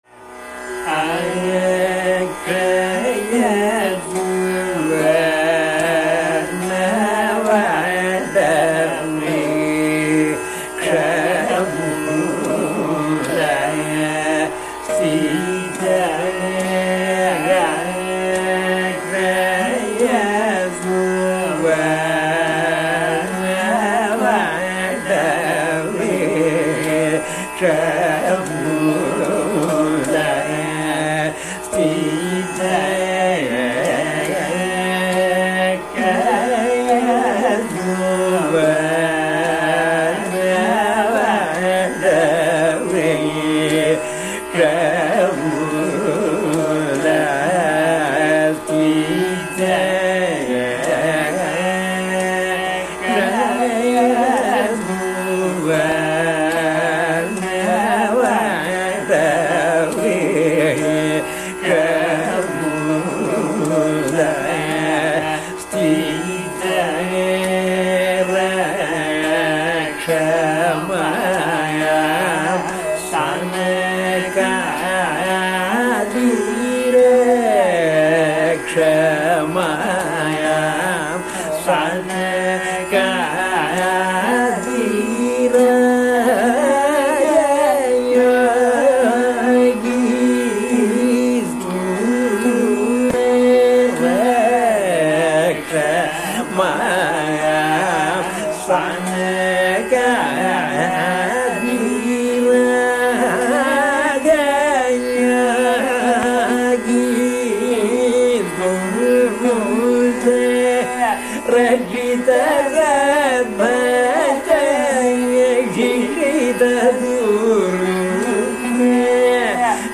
Anupallavi begins.
Again, each line of the anupallavi has its own particular melodic shape and repeats (with the exception of the last line), each time with minor variations.
Singer adds an improvised passage which connects the purvānga and uttarānga, summing up the pallavi and anupallavi in the way the caranam will next do in some detail.